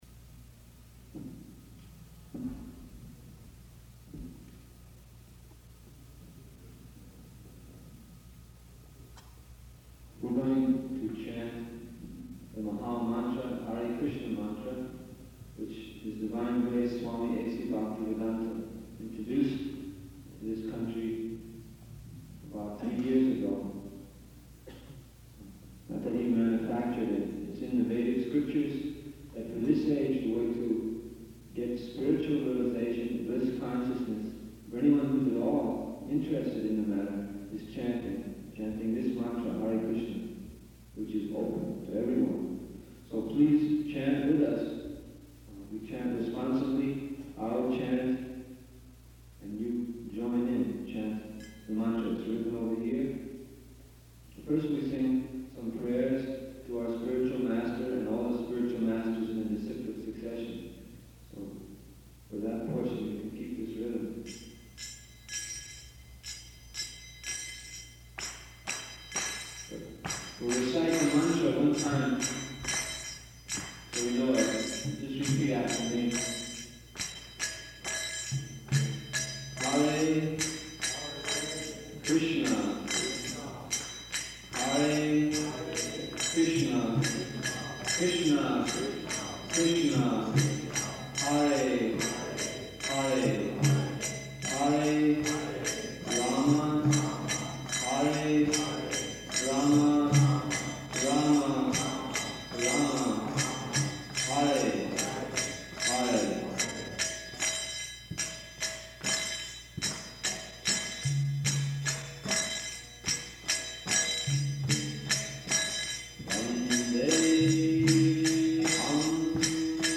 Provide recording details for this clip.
Lecture at Arlington Street Church Location: Boston